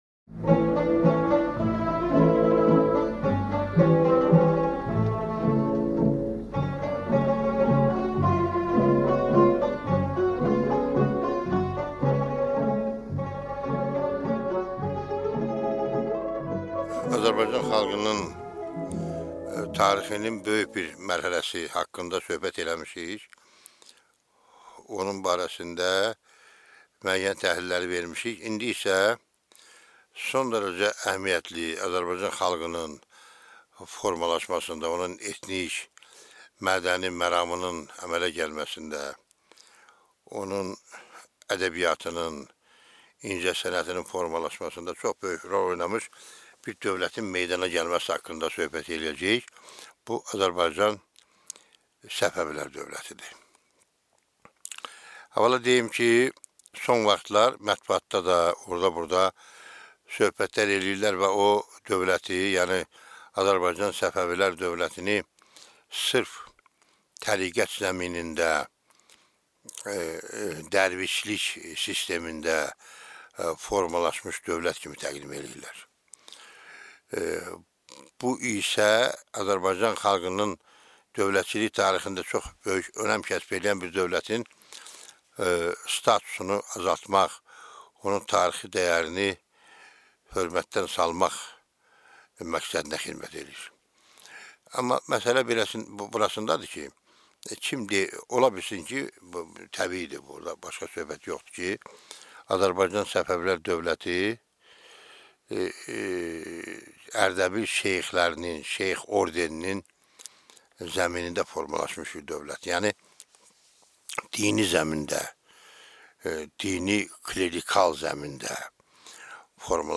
Аудиокнига Səfəvilər dövləti | Библиотека аудиокниг
Прослушать и бесплатно скачать фрагмент аудиокниги